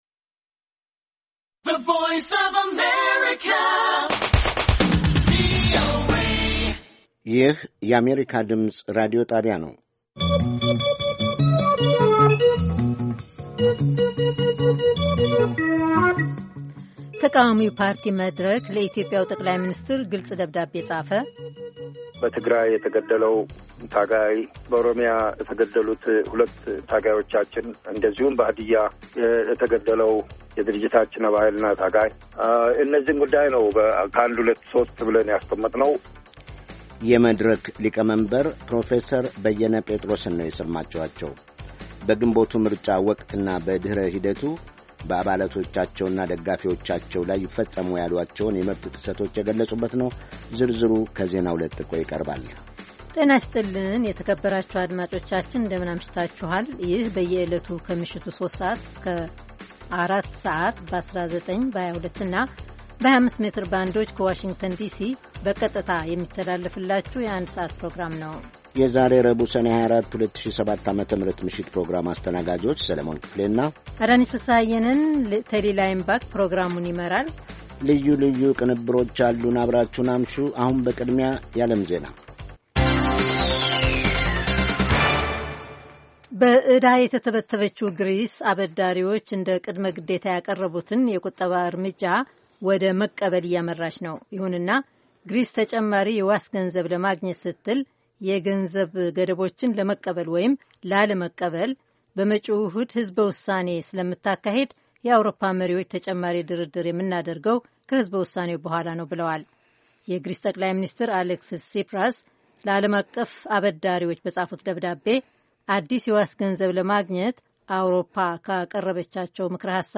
ቪኦኤ በየዕለቱ ከምሽቱ 3 ሰዓት በኢትዮጵያ ኣቆጣጠር ጀምሮ በአማርኛ፣ በአጭር ሞገድ 22፣ 25 እና 31 ሜትር ባንድ የ60 ደቂቃ ሥርጭቱ ዜና፣ አበይት ዜናዎች ትንታኔና ሌሎችም ወቅታዊ መረጃዎችን የያዙ ፕሮግራሞች ያስተላልፋል። ረቡዕ፡- ዴሞክራሲ በተግባር፣ ሴቶችና ቤተሰብ፣ አሜሪካና ሕዝቧ፣ ኢትዮጵያዊያን ባሜሪካ